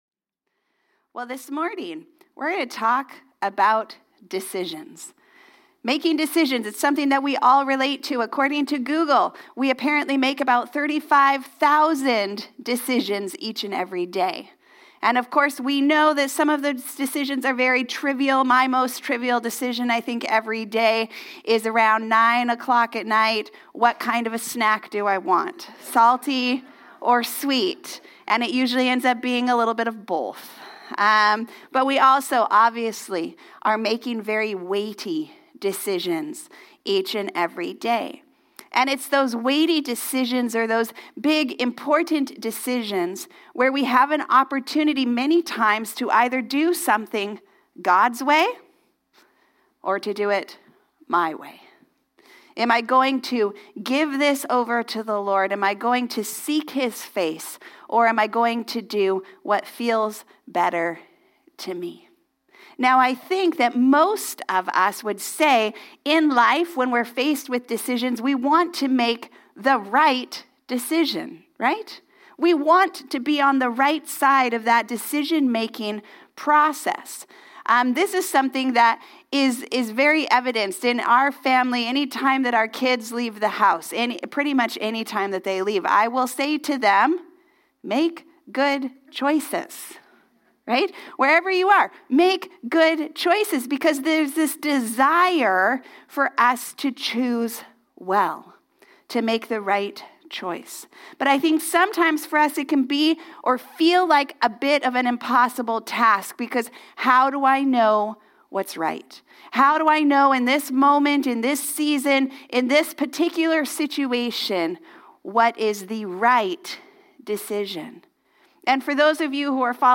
Sermons | Harvest Church